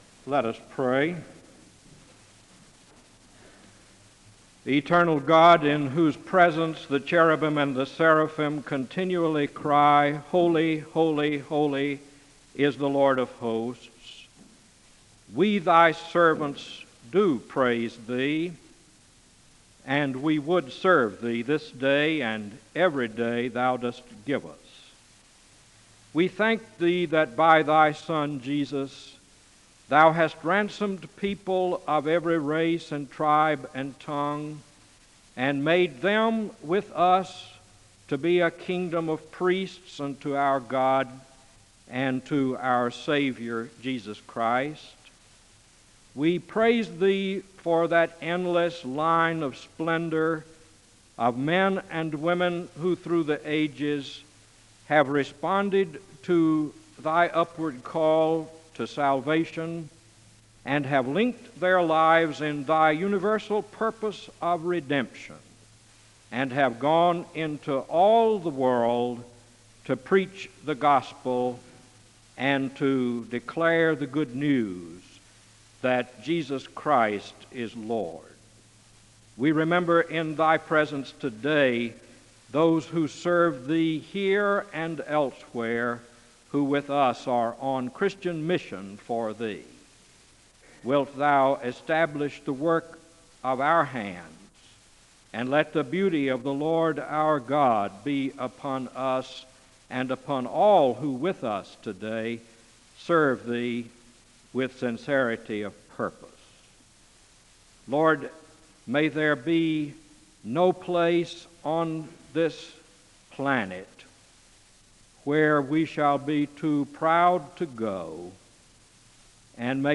The service begins with a word of prayer (00:00-03:00).
The choir sings the anthem (05:51-10:01).
SEBTS Chapel and Special Event Recordings SEBTS Chapel and Special Event Recordings